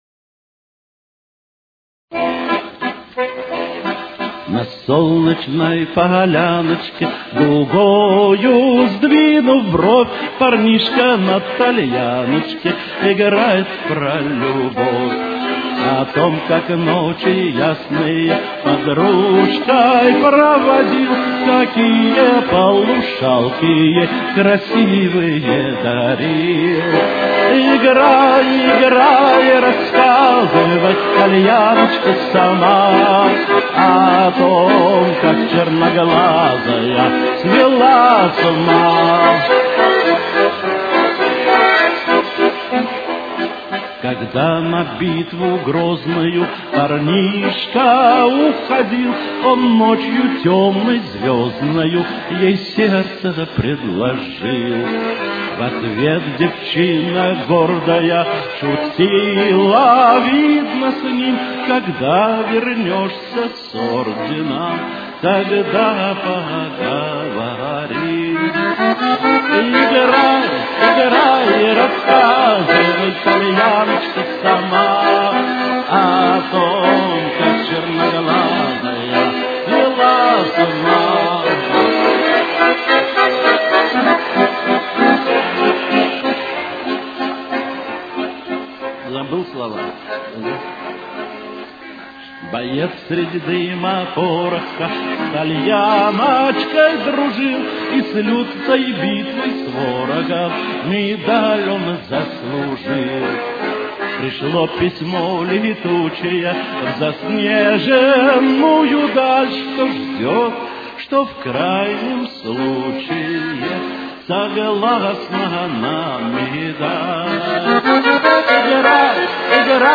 Ми минор. Темп: 100.